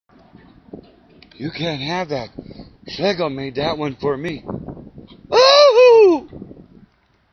The line is mine from a script. The scream is like Peter Lorre's from the Maltese Falcon.
Peter_Lorre.wma